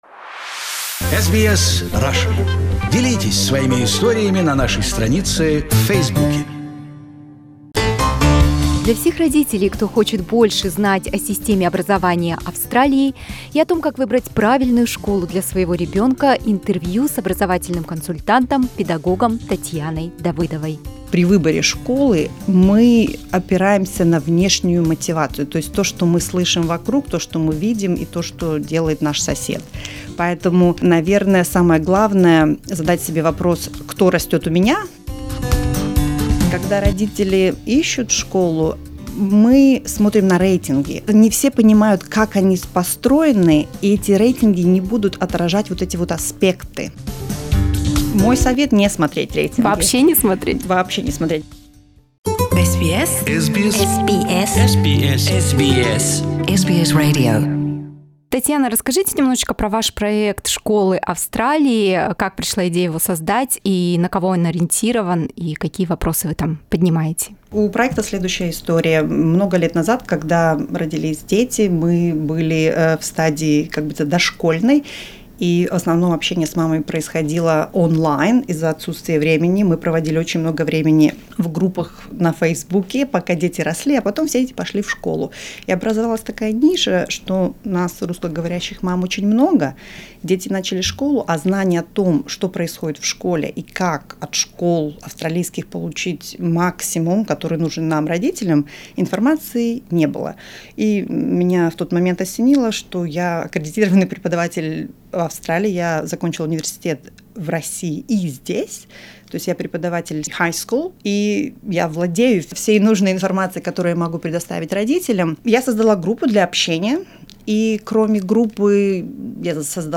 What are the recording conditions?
This is the first part of this conversation.